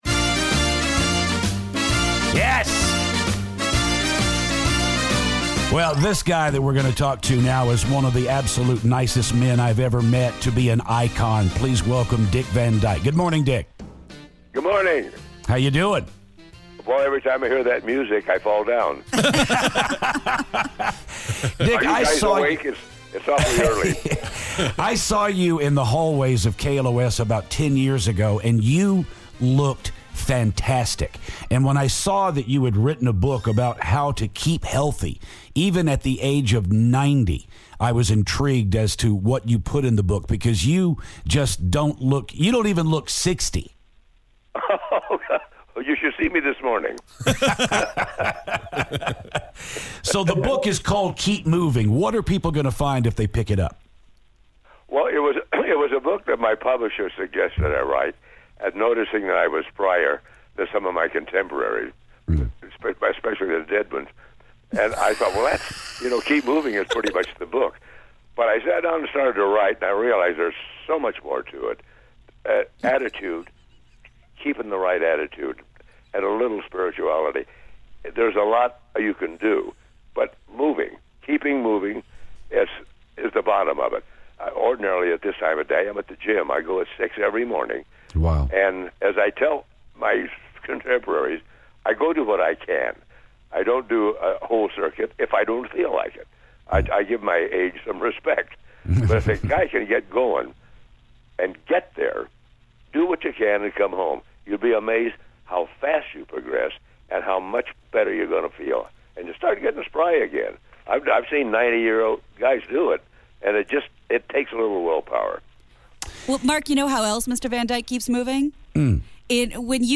The legendary Dick Van Dyke calls the show!